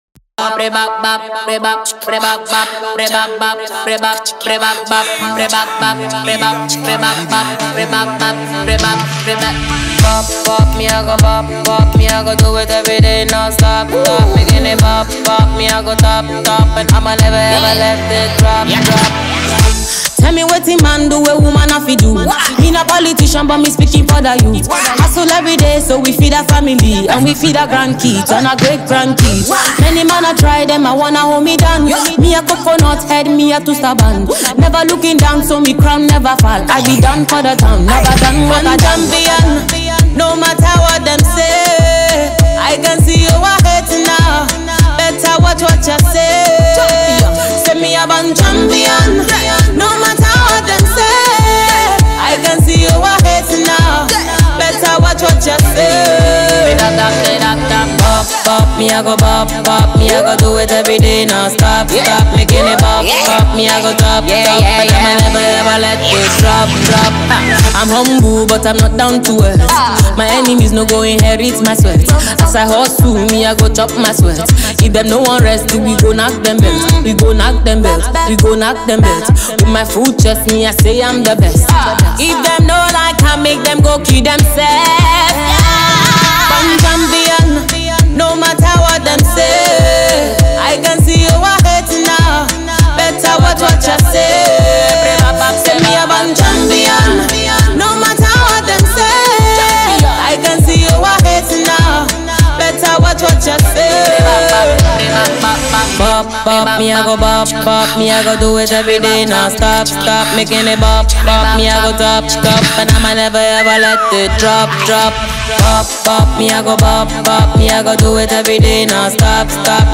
an iconic Nigerian female singer and songwriter.
Genre: Afrobeats